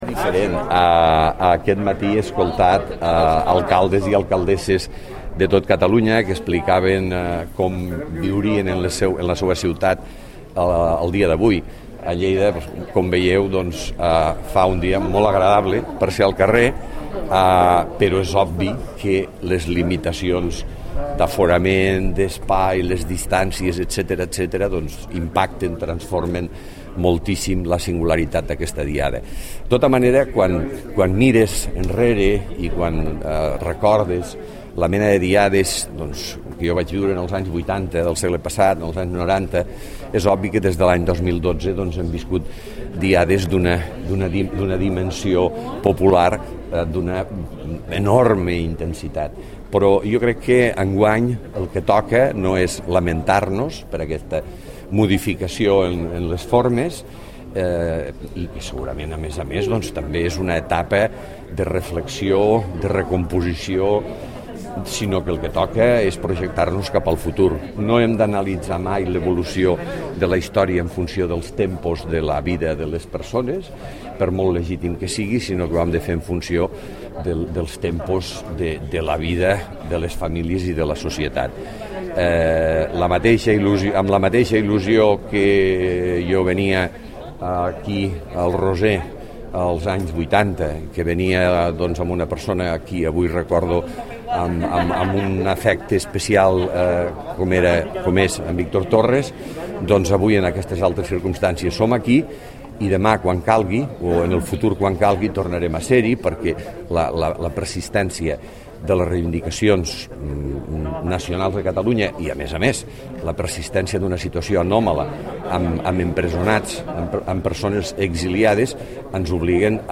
tall-de-veu-miquel-pueyo